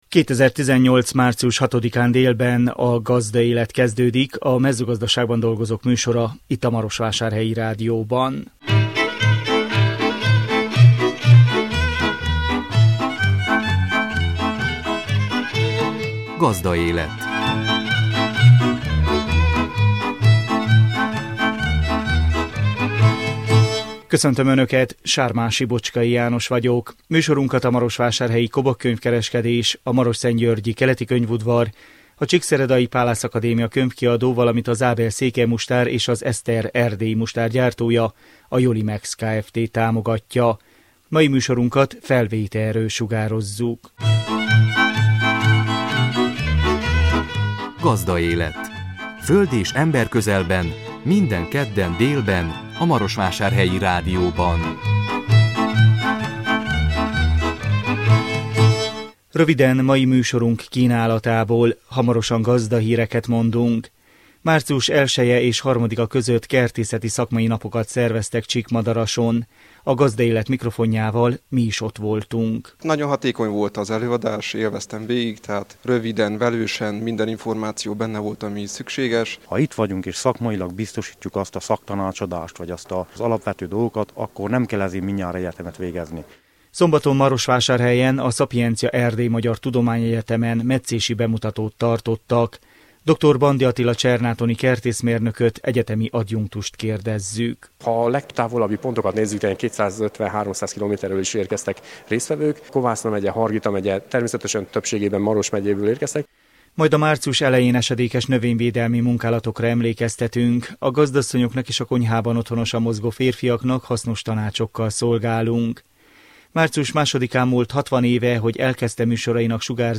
A Gazdaélet mikrofonjával mi is ott voltunk.
Emlékperceinkben régi hangfelvételekkel idézzük a múltat. Egyre nagyobb gondot jelent a gazdálkodóknak a barna csiga jelenléte.